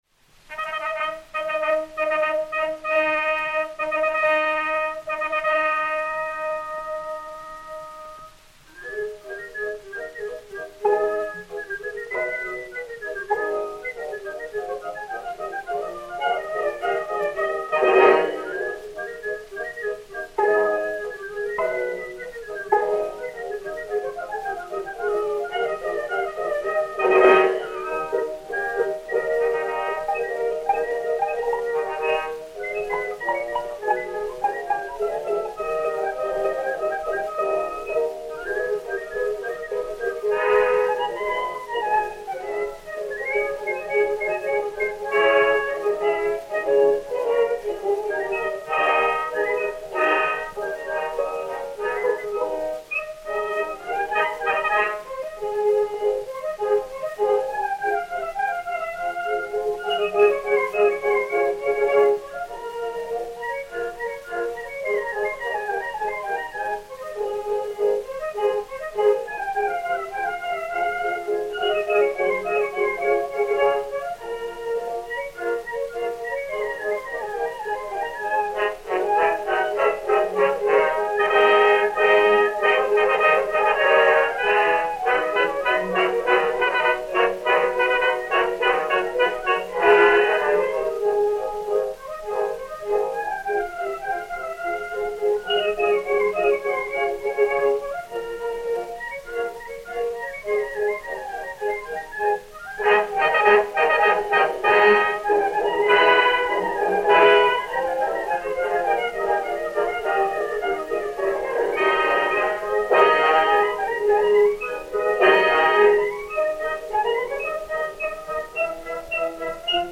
Orchestre des Concerts Colonne dir. Edouard Colonne
Pathé saphir 90 tours 8933, réédité sur 80 tours 6415, enr. à Paris en 1906/1907